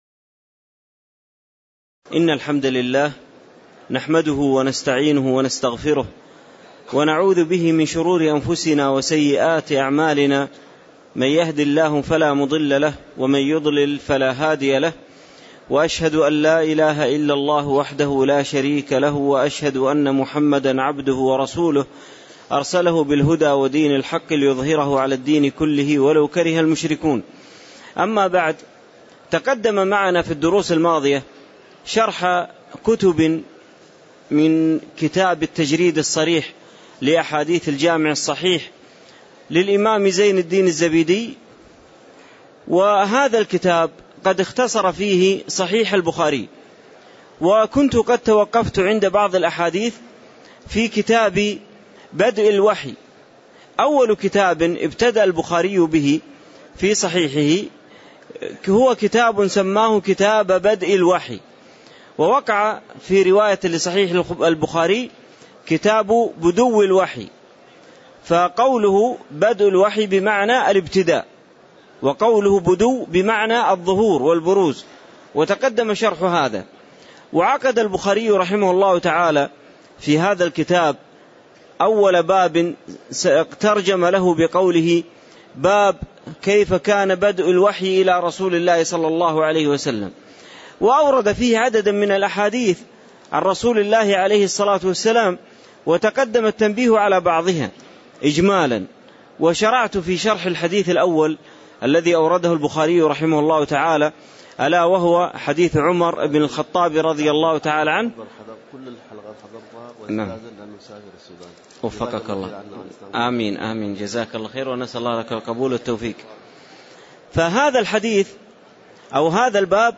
تاريخ النشر ٢٧ ذو الحجة ١٤٣٧ هـ المكان: المسجد النبوي الشيخ